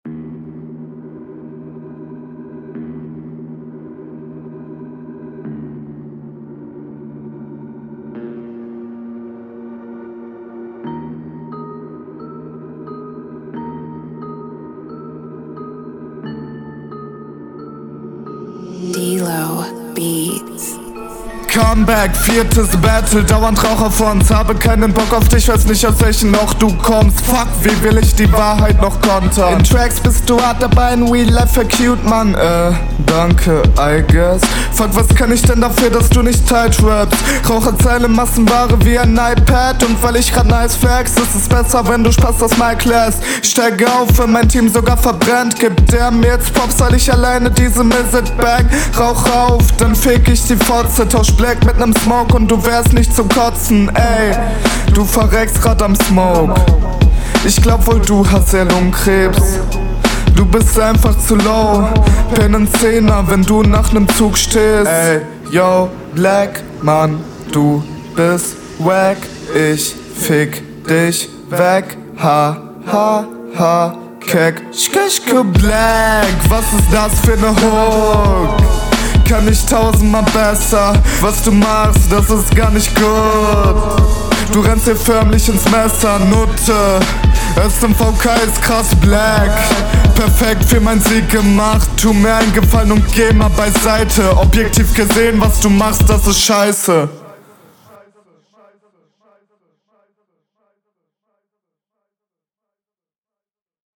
- Mix fühl' ich überhaupt nicht. Komische Peaks drin und die Doubles sind nicht on …